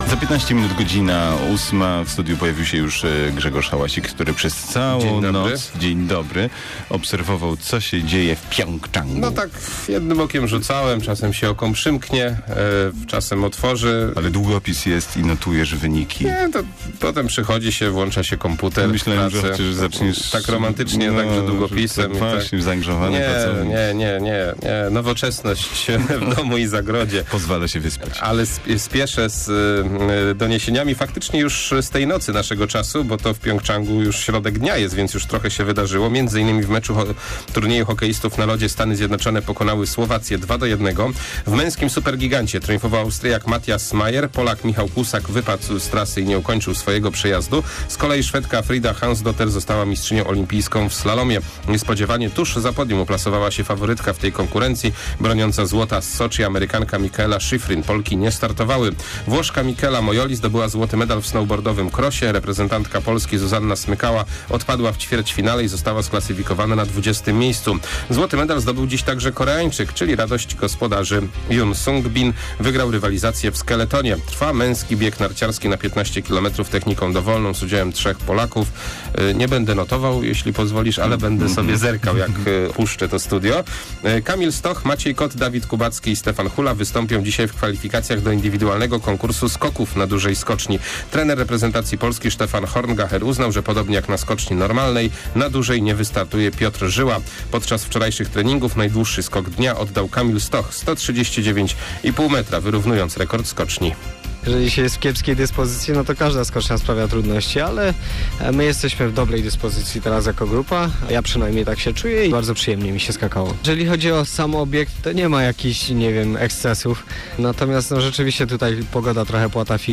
16.02 serwis sportowy godz. 7:45